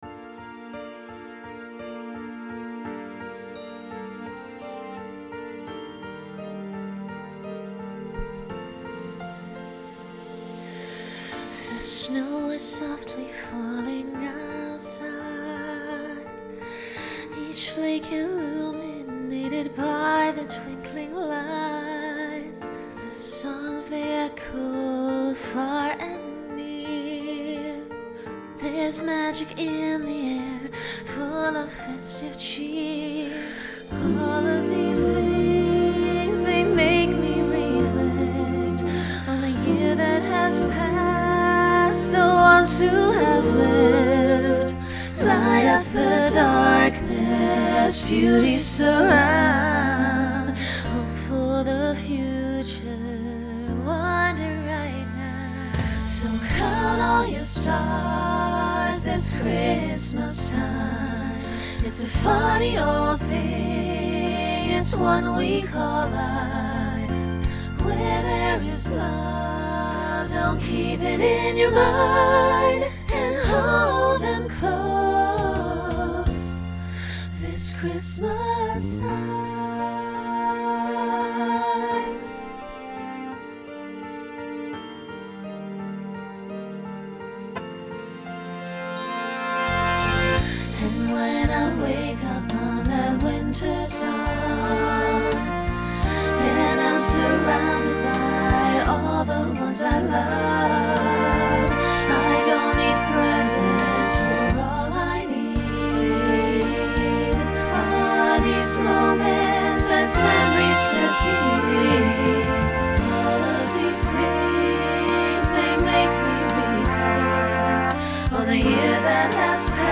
Holiday , Pop/Dance Instrumental combo
Ballad Voicing SATB